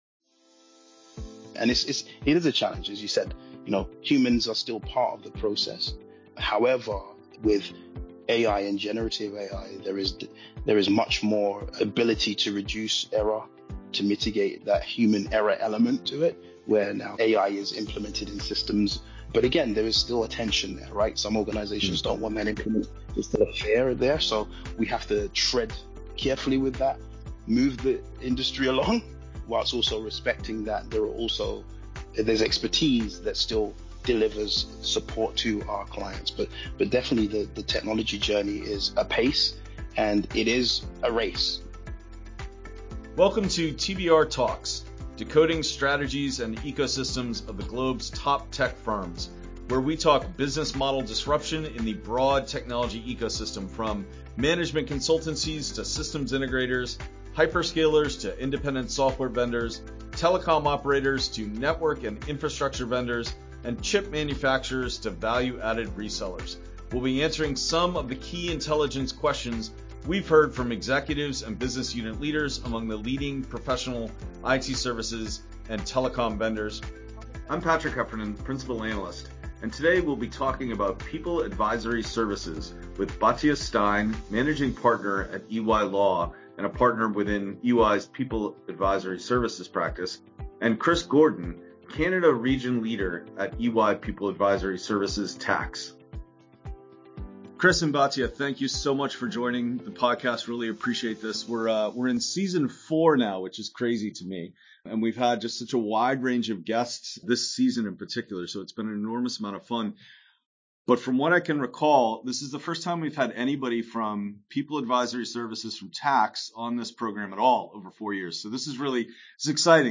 a discussion on EY’s AI strategy for its People Advisory Services practice